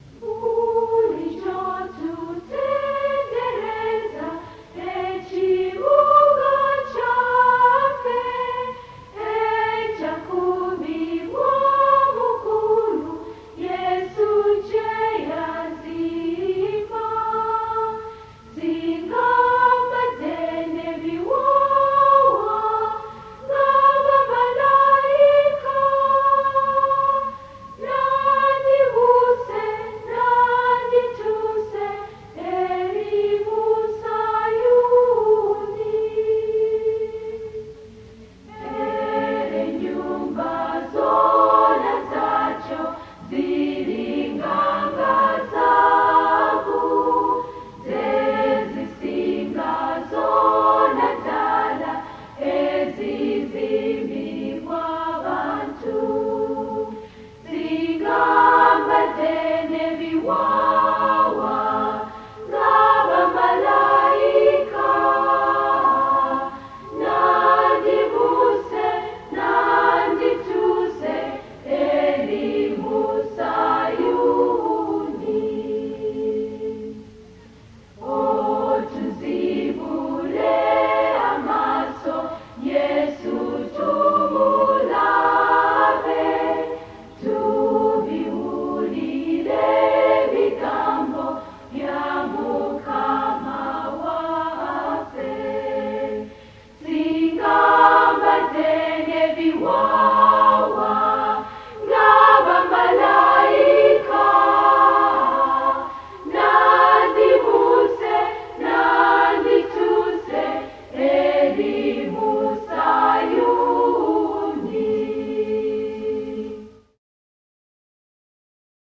In their Hour of Praise they sang 27 items.
Kiganda baakisimba dance
Praise drumming